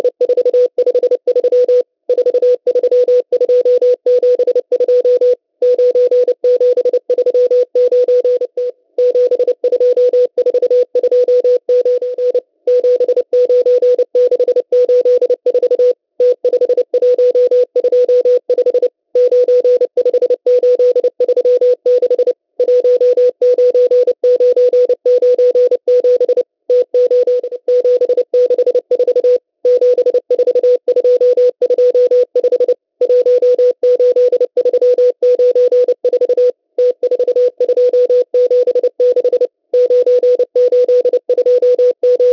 CW
10223 kHz